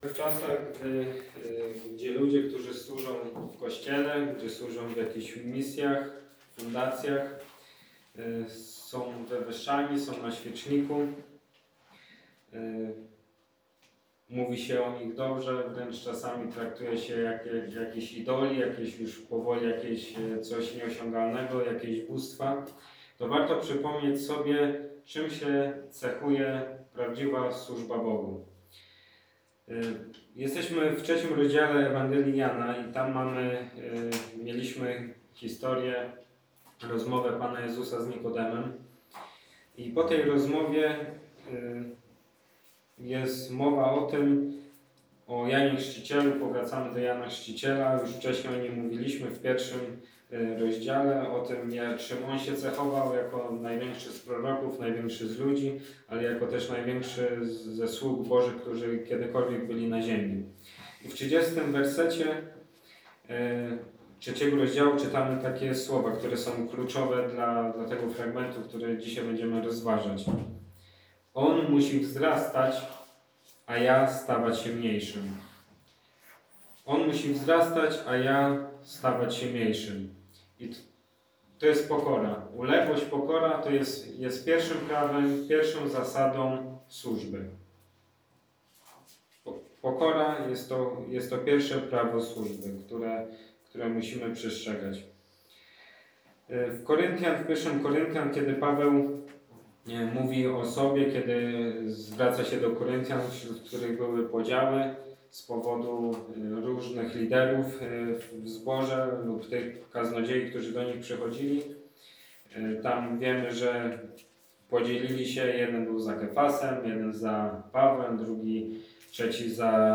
Kazanie niedziela 30.08.2020 – Kościół Wolnych Chrześcijan – Rudułtowy